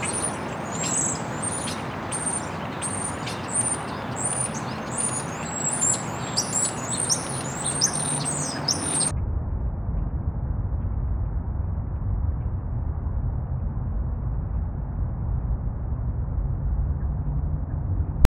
Zaradi manjše gostote Marsove atmosfere bo zvok tišji kot na Zemlji.
Kombinacija obeh (najprej na Zemlji, potem na Marsu) [.wav, 20sek]
Sounds-of-Mars_birds_Earth-Mars.wav